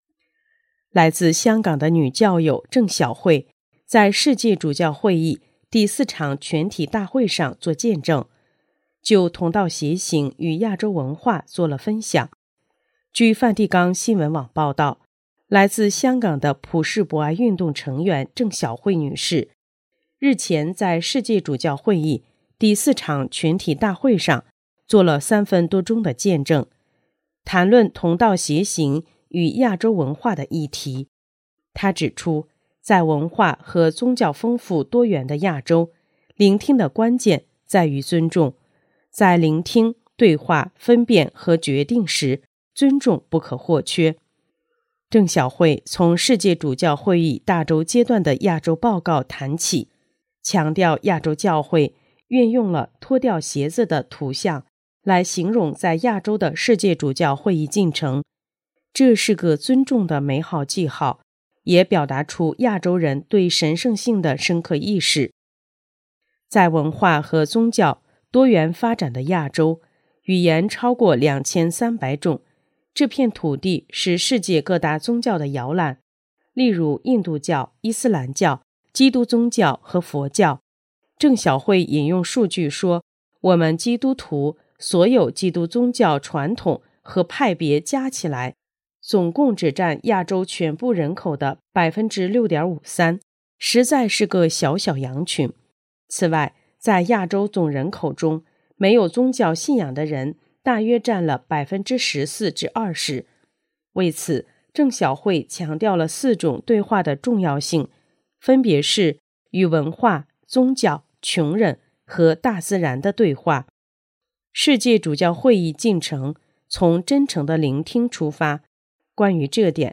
【公教新闻】| 世界主教会议见证：同道偕行与亚洲文化